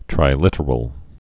(trī-lĭtər-əl)